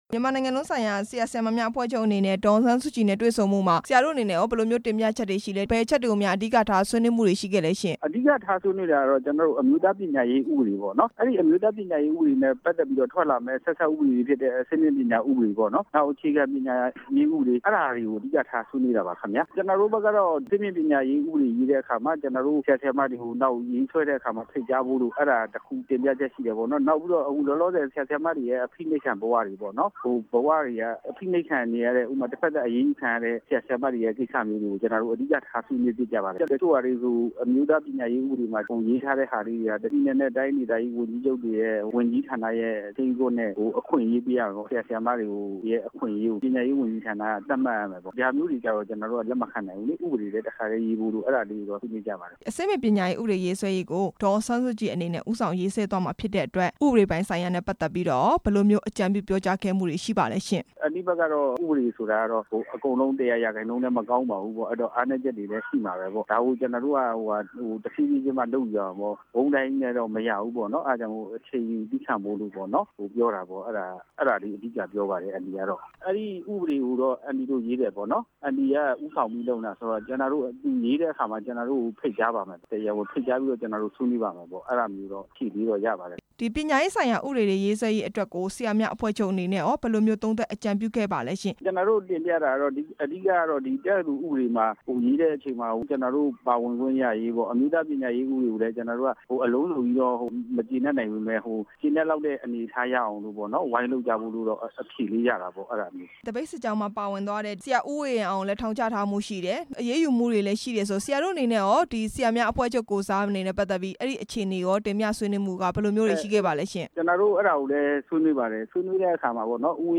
ဒေါ်အောင်ဆန်းစုကြည်နဲ့ ဆရာ၊ ဆရာမများအဖွဲ့ချုပ် တွေ့ဆုံတဲ့အကြောင်း မေးမြန်းချက်